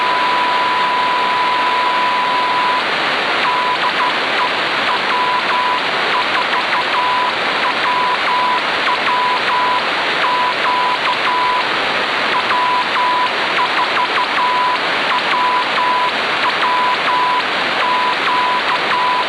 excerpt from a 122GHz QSO (the file is a 415kB 19 sec .WAV file).
Path distance is 61.7km.